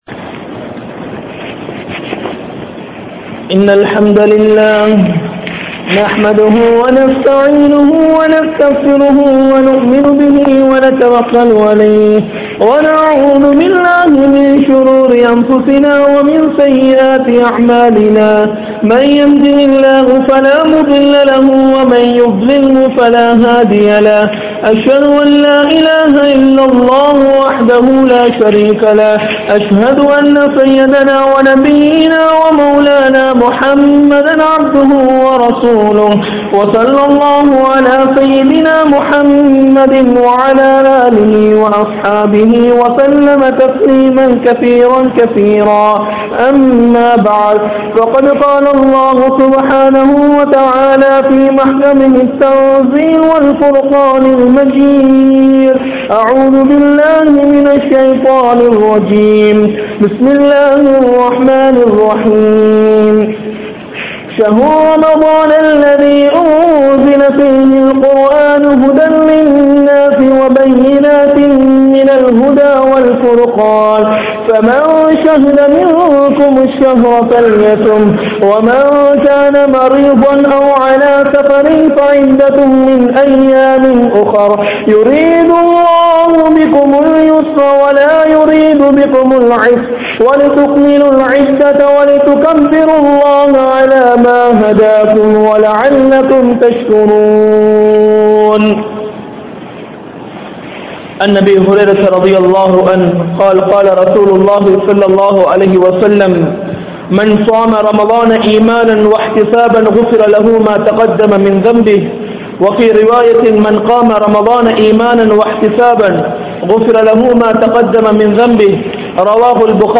Nantri Ketta Manitharhal (நன்றி கெட்ட மனிதர்கள்) | Audio Bayans | All Ceylon Muslim Youth Community | Addalaichenai